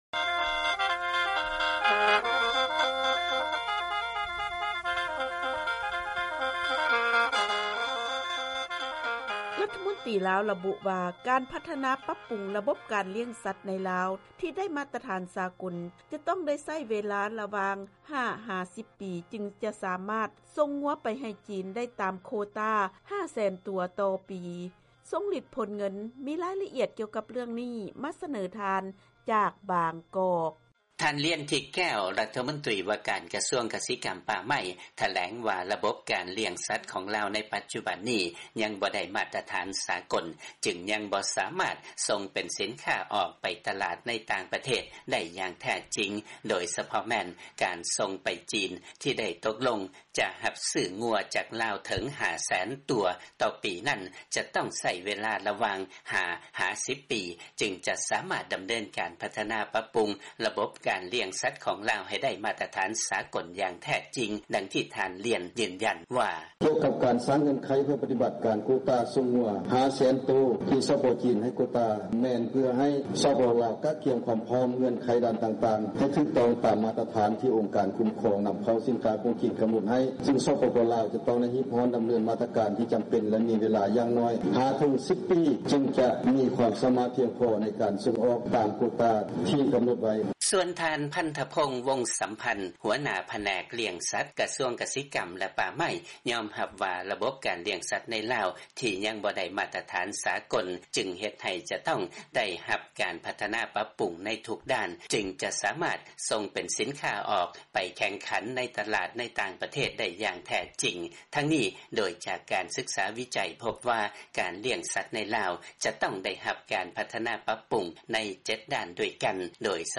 ເຊີນຟັງລາຍງານ ການລ້ຽງສັດໃນລາວ ຍັງບໍ່ທັນໄດ້ມາດຕະຖານສາກົນ ຈຶ່ງຕ້ອງໃຊ້ເວລາ ປັບປຸງ ເຖິງ 5 -10 ປີ